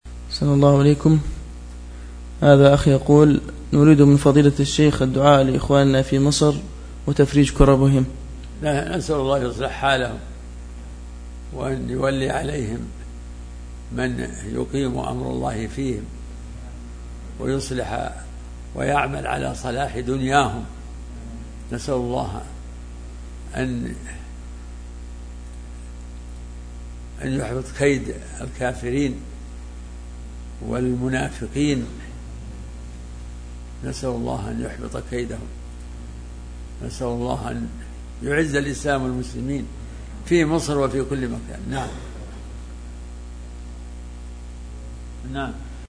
دعاء الشيخ لأهل مصر